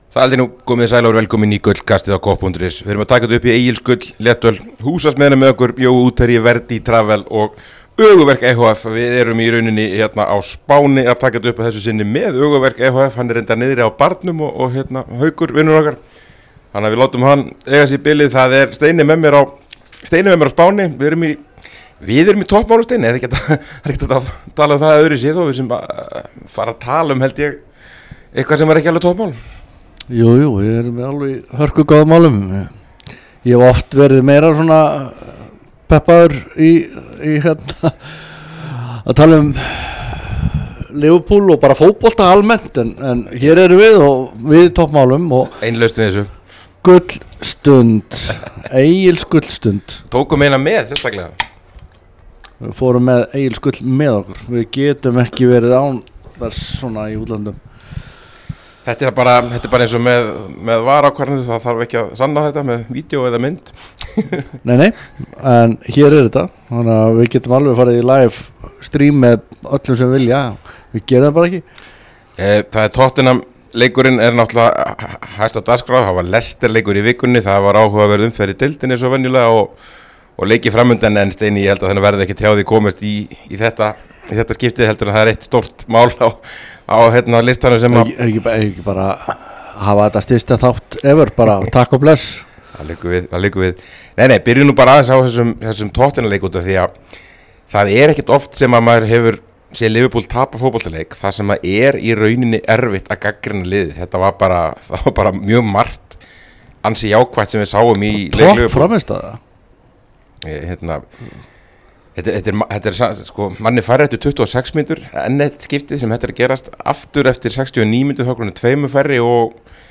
Helgin gerð upp frá Spáni að þessu sinni.